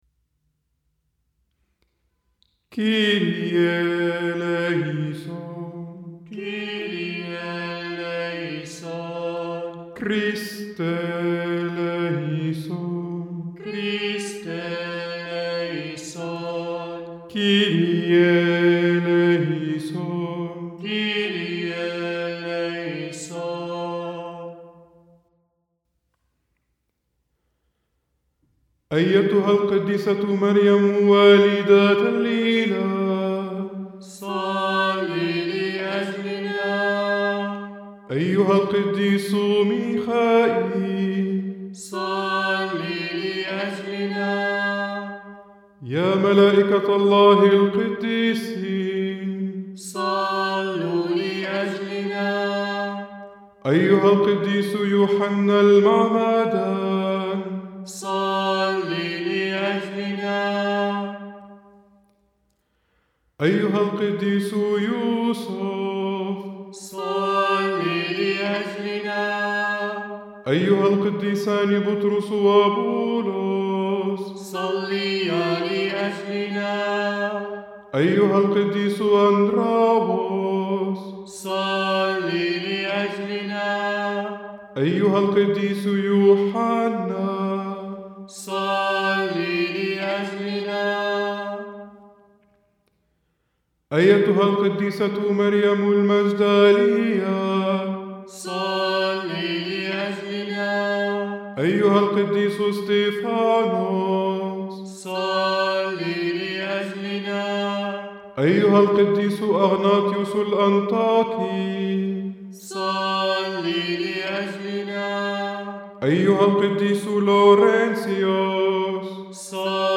P-356-H.-Saturday-Saints-Litany.mp3